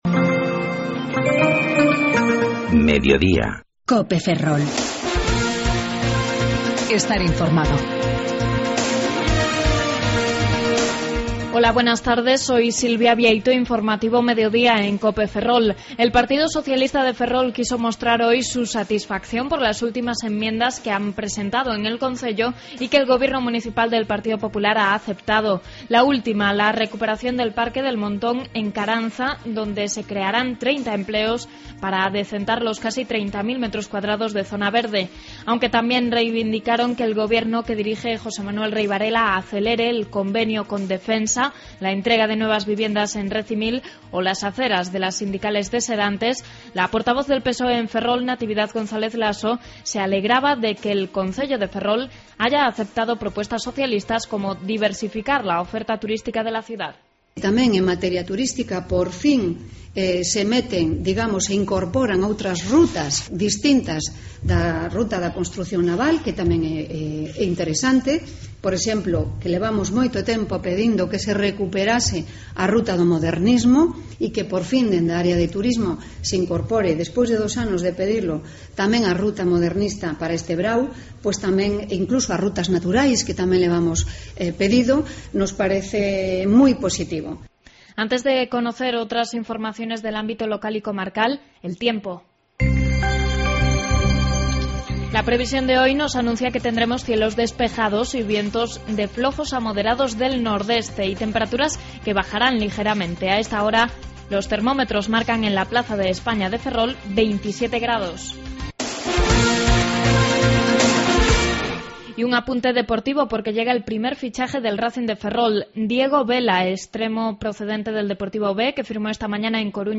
14.25 Informativo Mediodía Cope Ferrol